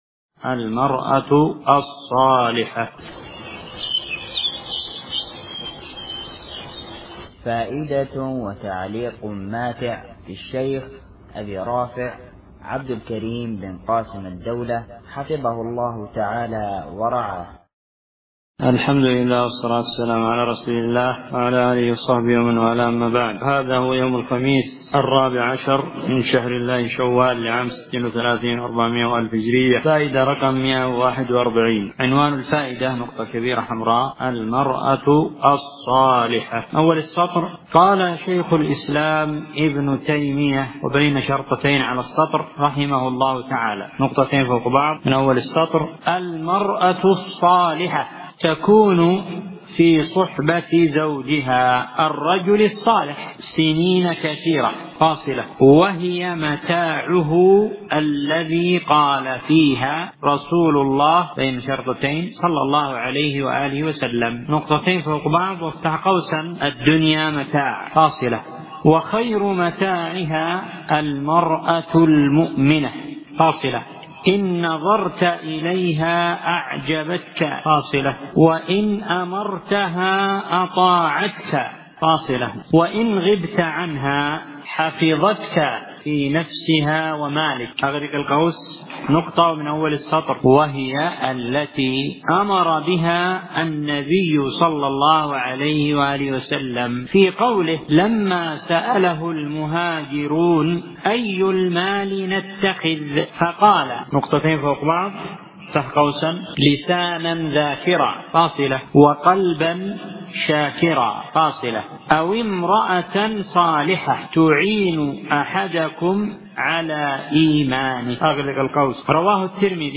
في مسجد الرحمن الجراحي الحديدة اليمن